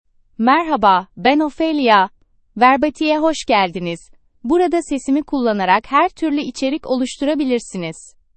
Ophelia — Female Turkish (Turkey) AI Voice | TTS, Voice Cloning & Video | Verbatik AI
Ophelia is a female AI voice for Turkish (Turkey).
Voice sample
Listen to Ophelia's female Turkish voice.
Female
Ophelia delivers clear pronunciation with authentic Turkey Turkish intonation, making your content sound professionally produced.